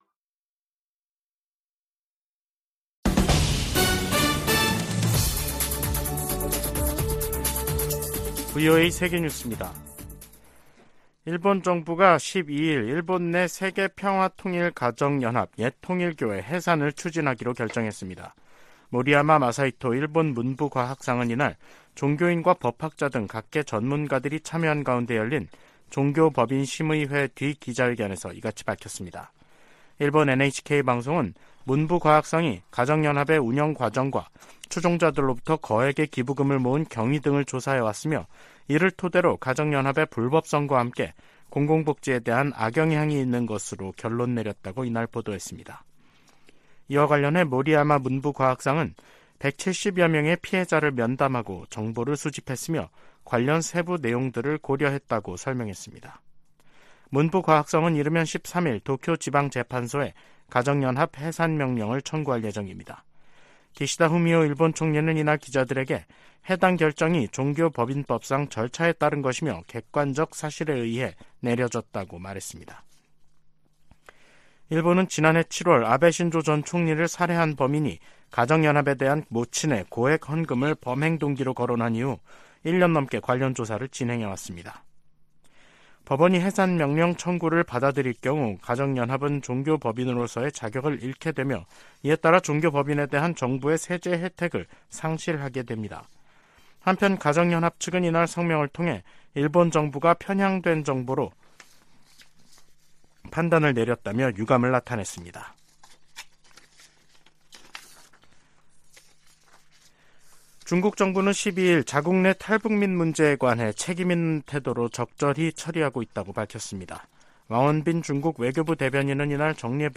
VOA 한국어 간판 뉴스 프로그램 '뉴스 투데이', 2023년 10월 12일 3부 방송입니다. 백악관이 이스라엘 지상군의 가자지구 투입에 대비해 민간인 대피 대책을 논의 중이라고 밝혔습니다. 한국을 방문한 미국 상원의원들이 윤석열 한국 대통령과 만나 대북 상호 방위에 대한 초당적 지지를 재확인했습니다. 9.19 남북군사합의가 팔레스타인 무장 정파 하마스식의 북한 기습 도발에 대한 감시·정찰 능력을 제한한다고 미국 전문가들이 지적했습니다.